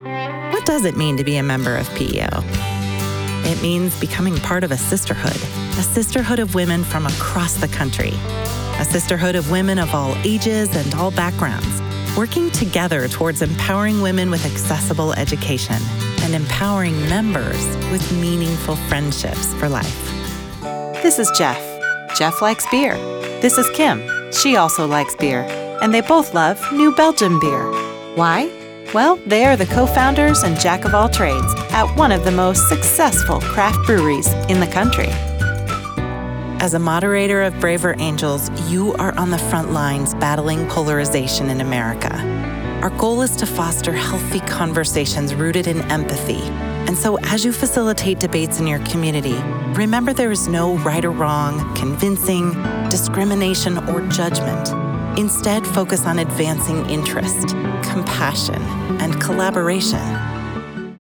SOVAS nominated voice actor with a lower-pitch friendly, articulate American sound that is both confident and warm.
Explainer Demo
Middle Aged
Explainer demo Final mixed_0.mp3